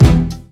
Kick22.wav